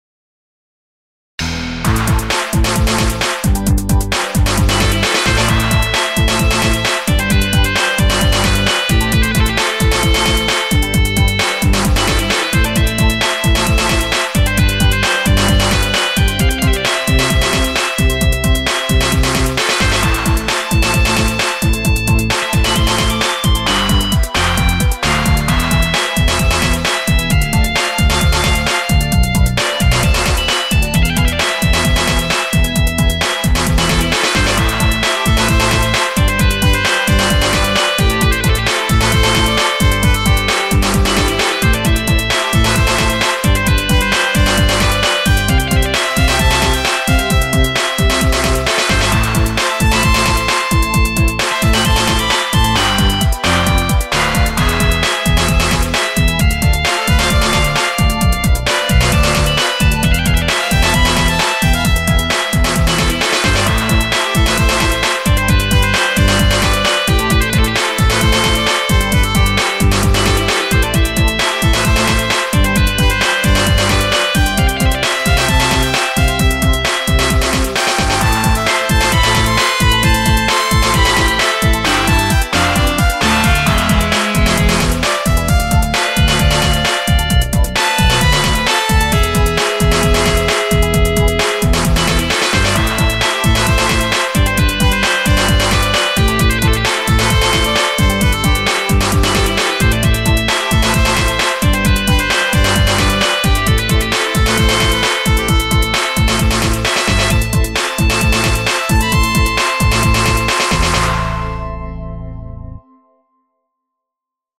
BGM
EDMエレクトロニカファンタジー